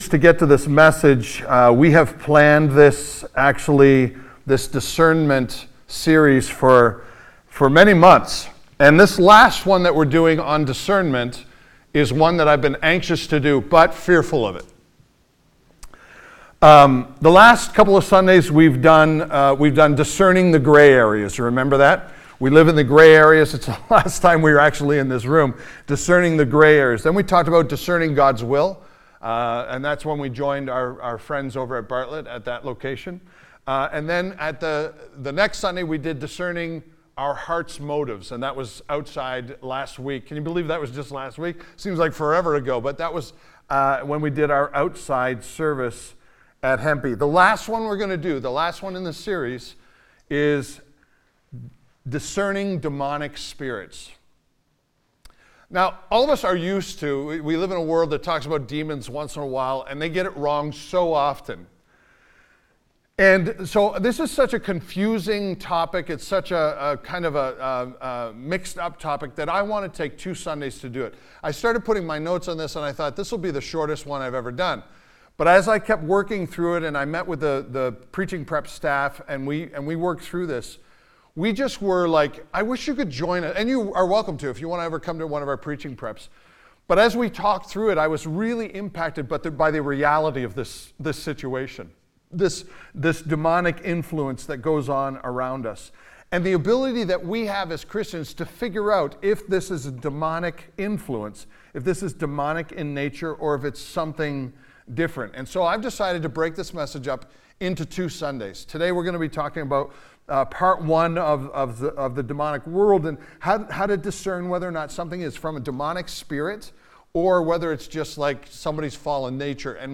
This sermon discusses how to discern spirits and demonic influence with a Godly perspective.&nbsp